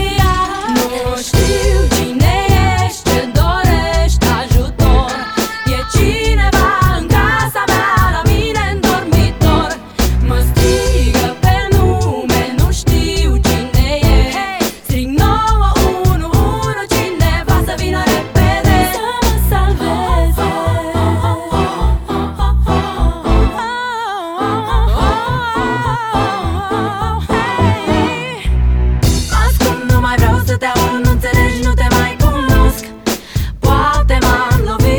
Contemporary R B R B Soul
Жанр: R&B / Соул